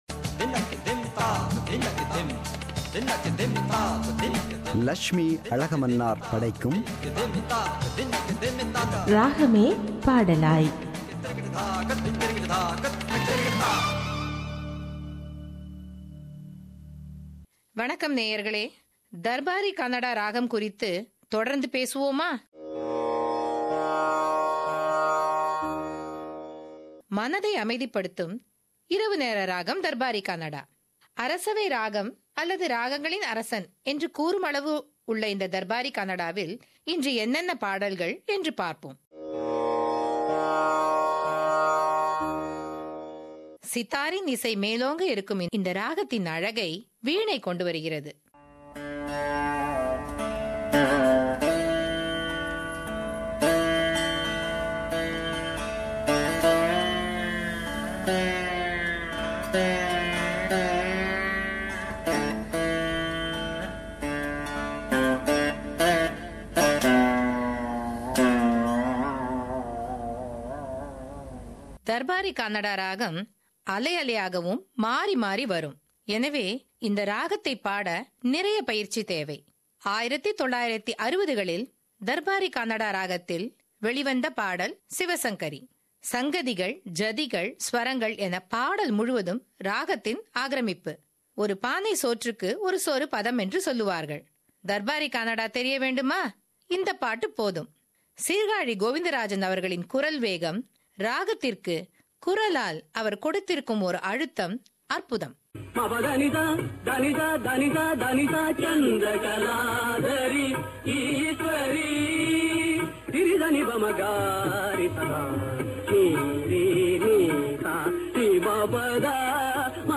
“Ragame Padalaay” – Musical Program –Part 8